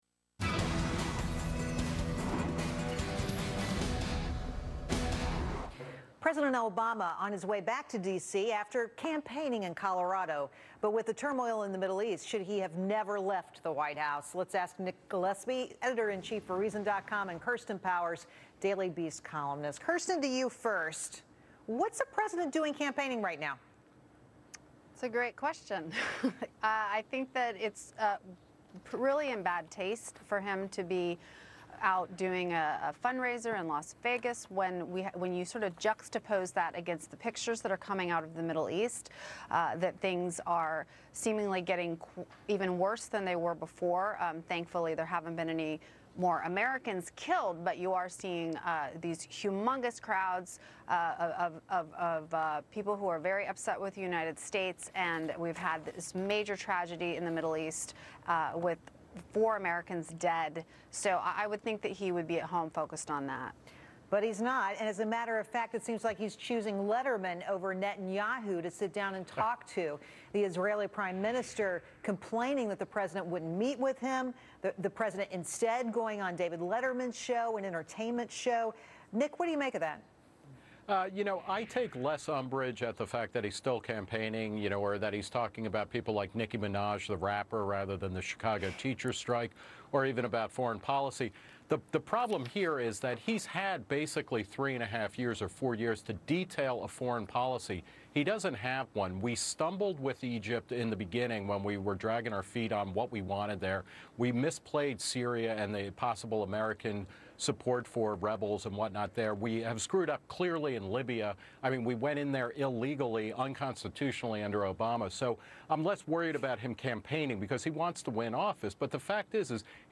Reason TV's Nick Gillespie appears on The Willis Report to discuss President Obama's campaign for reelection having priority over foreign policy meetings. Nick also comments on Michelle Obama's appearance on The Dr. Oz Show claiming that obesity is a threat to national security.